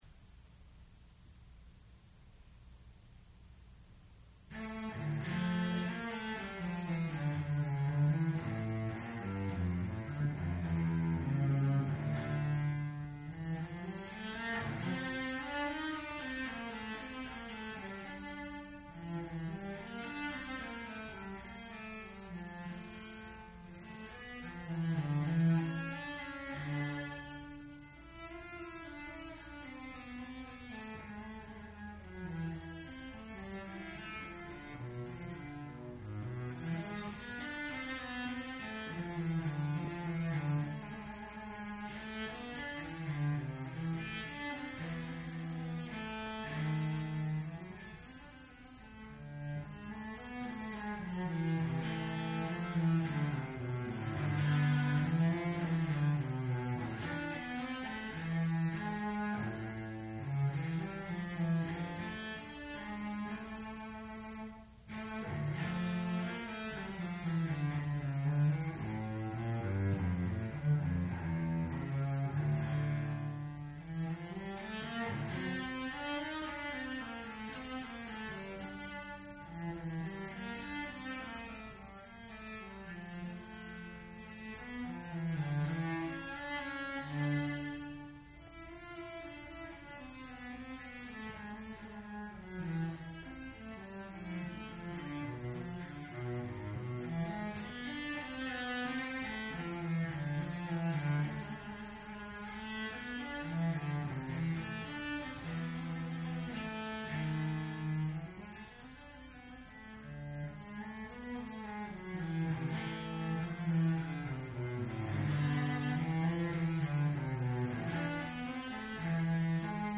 sound recording-musical
classical music
Senior Recital
violoncello